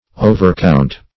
overcount \o`ver*count"\, v. t.